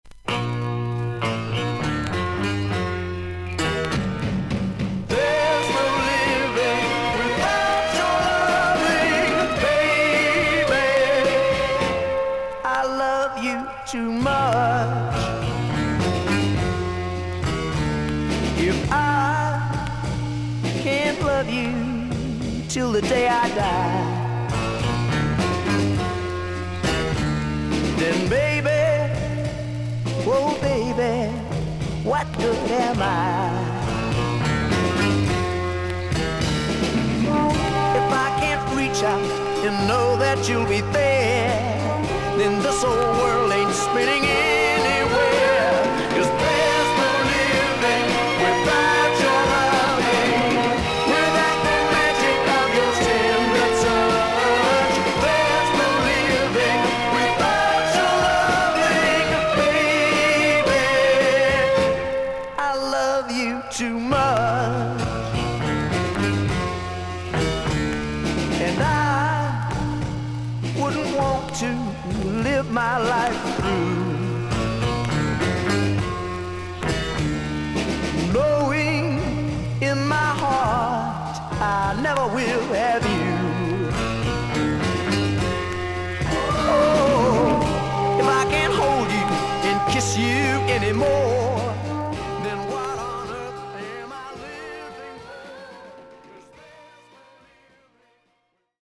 B1に1cmのキズあり、少々軽い周回ノイズあり。
ほかはVG++〜VG+:少々軽いパチノイズの箇所あり。少々サーフィス・ノイズあり。クリアな音です。